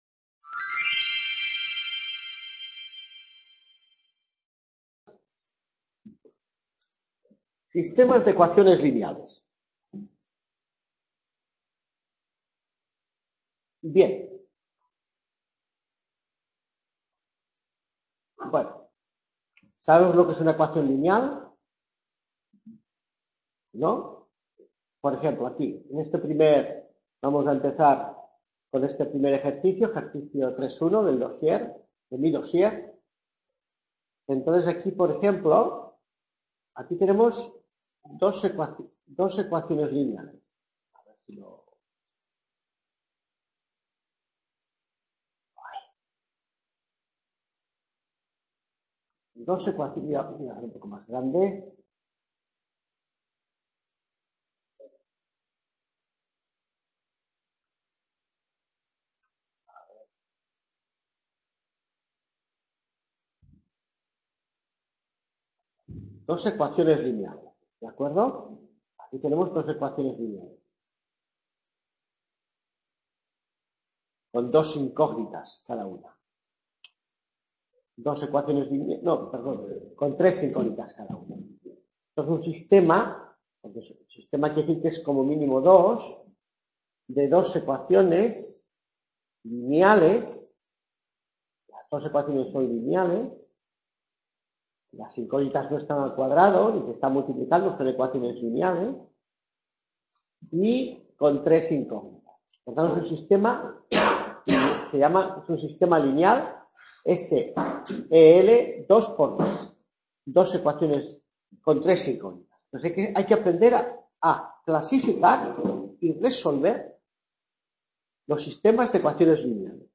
Clase 1 mates 2 q2 1415 Sistemas de ecuaciones lineales | Repositorio Digital